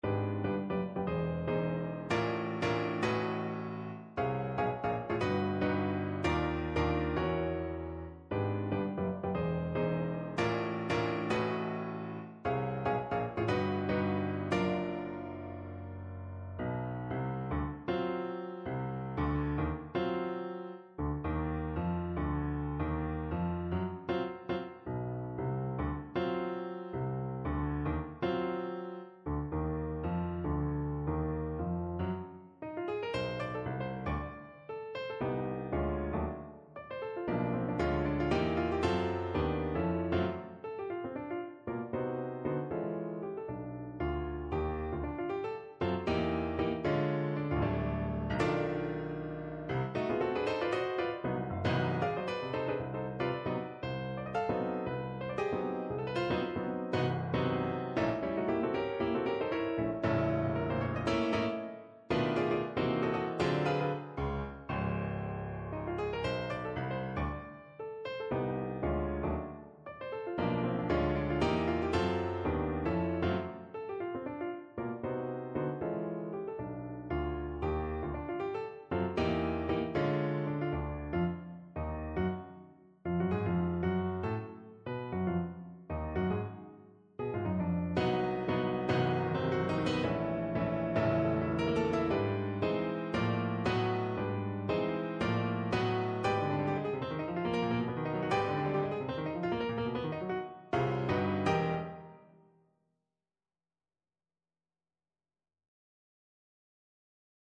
Jazzová témata / Jazz Themes